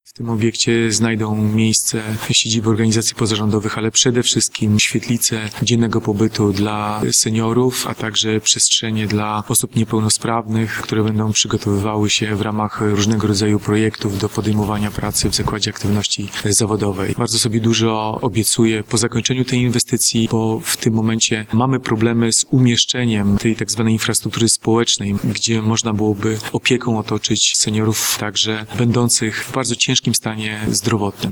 – Będziemy chcieli połączyć w nowym centrum kilka funkcji prospołecznych – zapowiada burmistrz Robert Krupowicz.